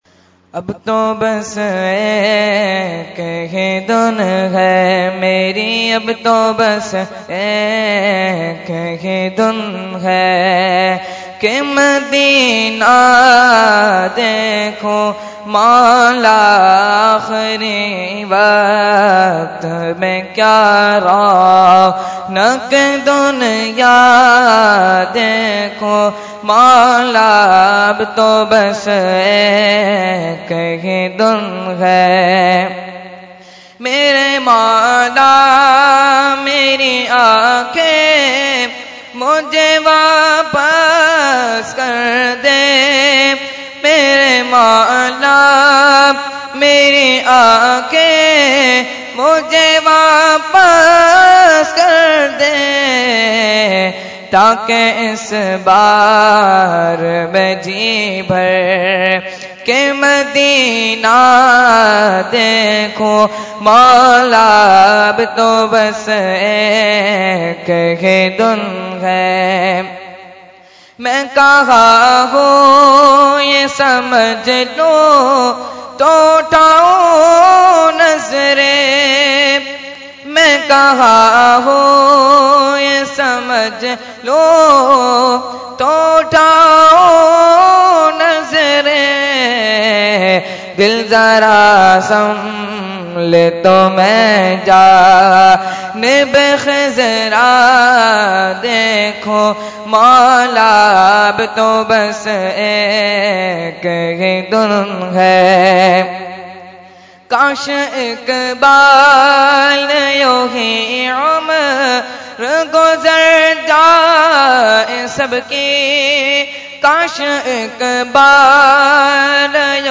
Category : Naat | Language : UrduEvent : Khatam Hizbul Bahr 2016